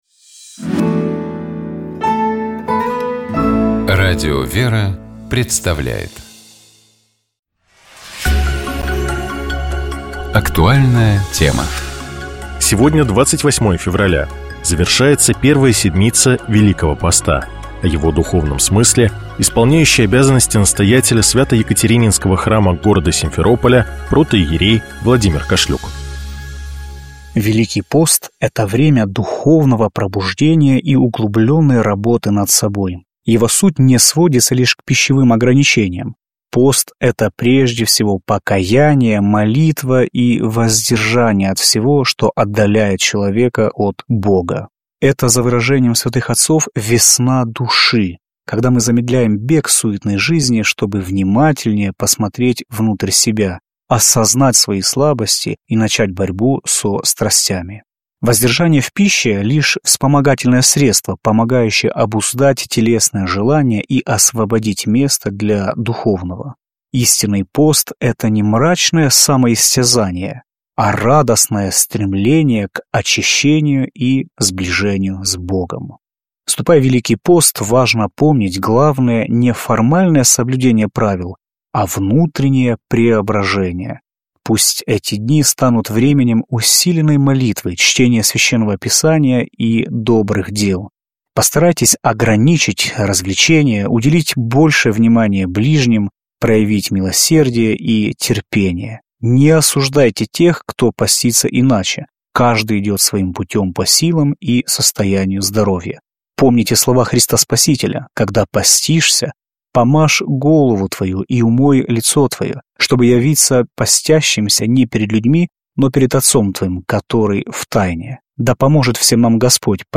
Каждый день мы выбираем самые насущные темы и приглашаем гостей рассуждать вместе с нами.